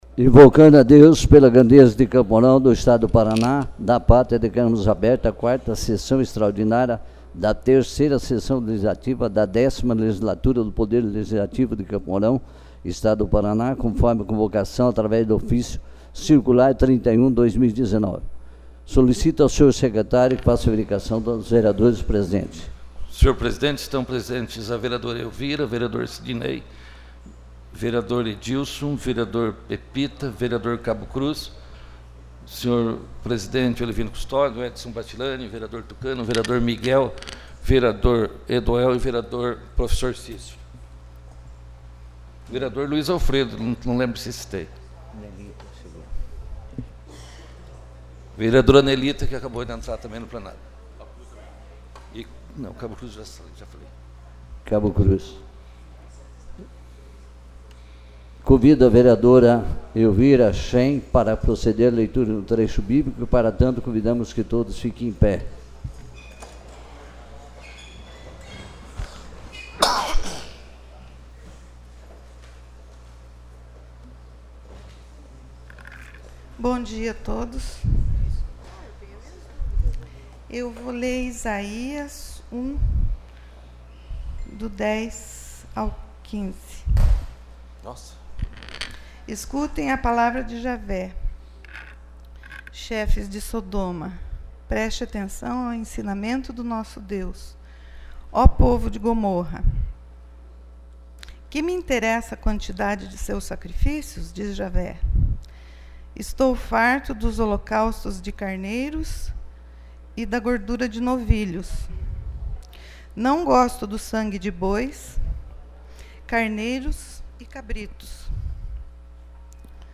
4ª Sessão Extraordinária